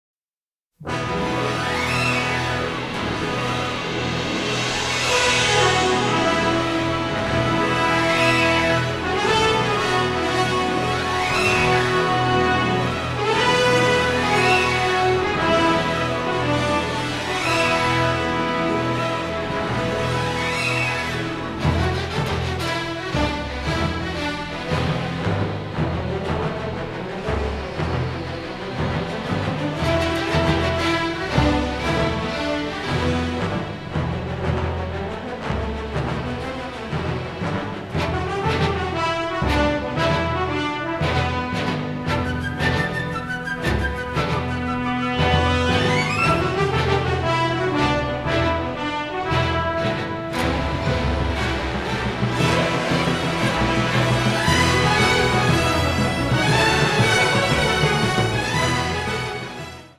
a sensitive, dramatic, delicate score with an Irish flavor